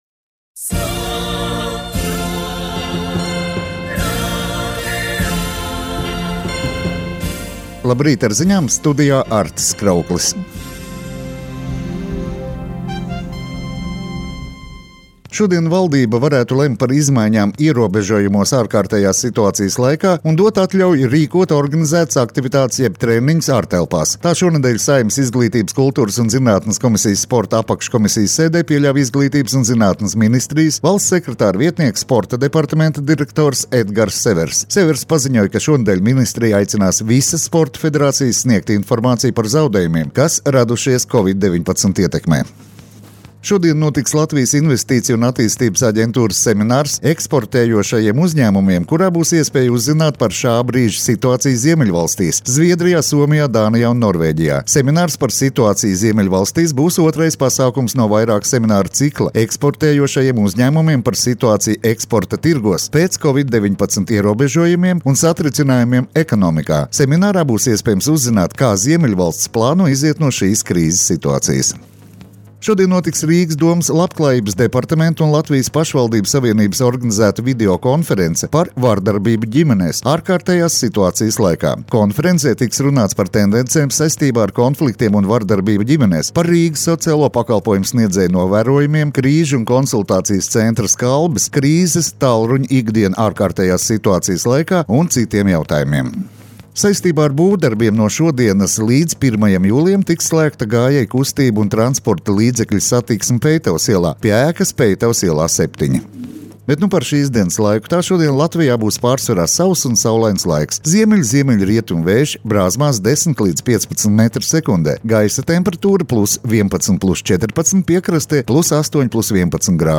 Radio Skonto ziņas Rīta programmā 07.05.